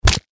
Звуки удара кулаком
На этой странице собраны реалистичные звуки удара кулаком — от легких хлопков до мощных нокаутирующих ударов.